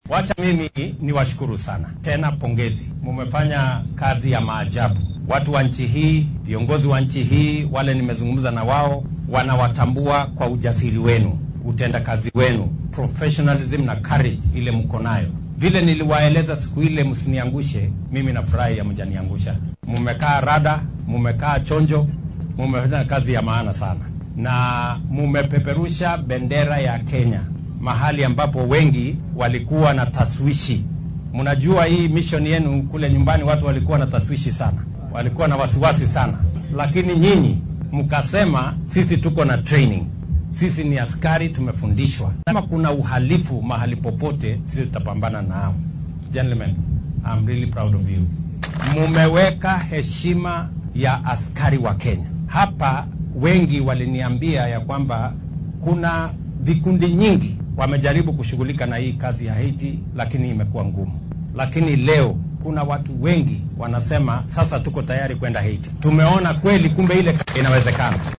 Xilli uu madaxweynaha ciidamadan kula hadlay dalka Haiti ayuu sheegay inuu ku faanaya kaalintooda kaga aadan nabad sugidda waddanka Haiti.